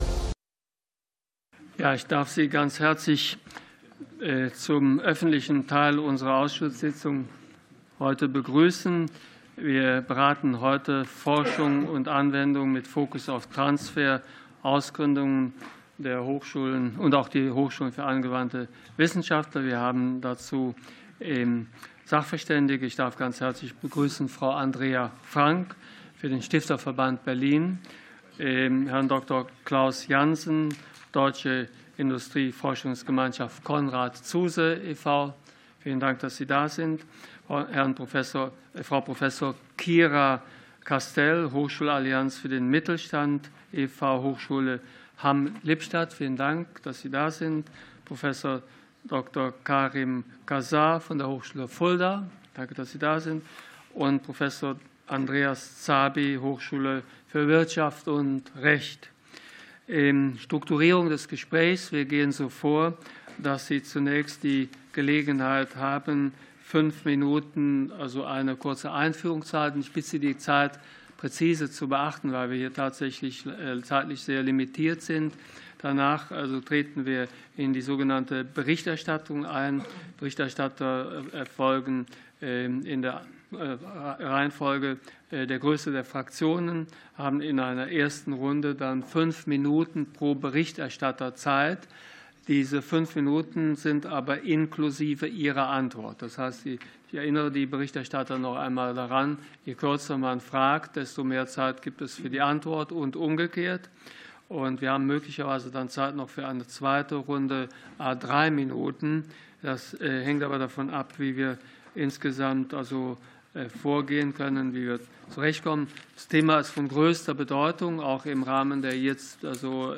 Fachgespräch des Ausschusses für Forschung, Technologie, Raumfahrt